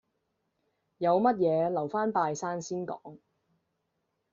Голоса - Гонконгский 282